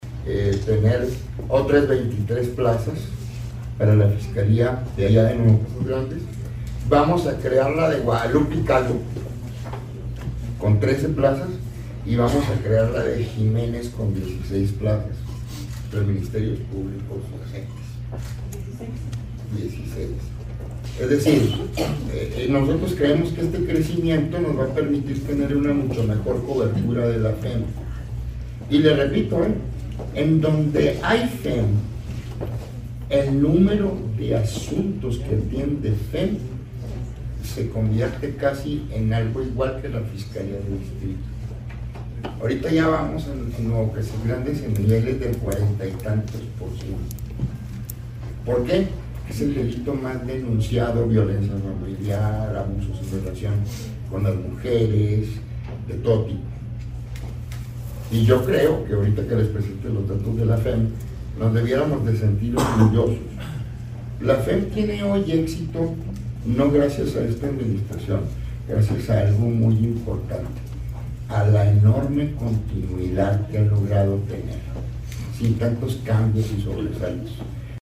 AUDIO: CÉSAR JÁUREGUI MORENO, FISCAL GENERAL DEL ESTADO (FGE)
Chihuahua, Chih.- Durante su comparecencia en el Congreso del Estado, el fiscal General del Estado, César Jáuregui, anunció la apertura de nuevas oficinas de la Fiscalía Especializada en la Mujer y Delito Contra la Familia en los municipios de Guadalupe y Calvo y Jiménez, ambos adscritos a la Fiscalía General del Estado (FGE) de Distrito Zona Sur.